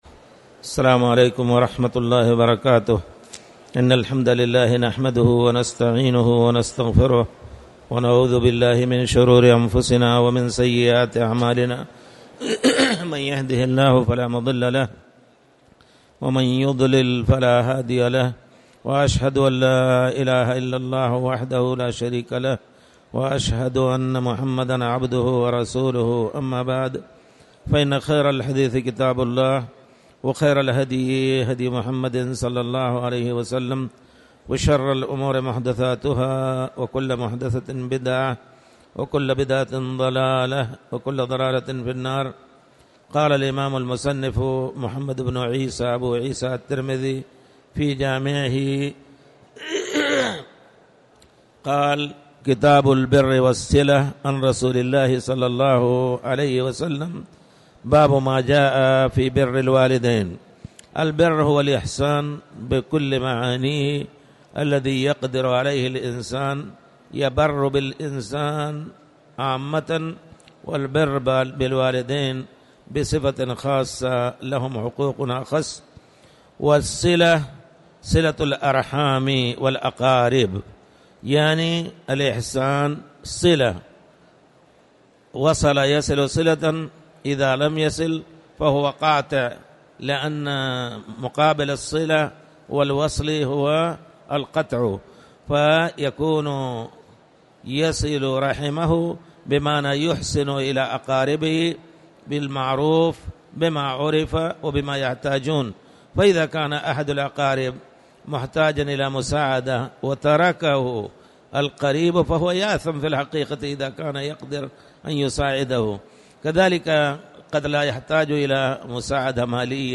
تاريخ النشر ١٩ شوال ١٤٣٨ هـ المكان: المسجد الحرام الشيخ